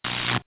bomb.au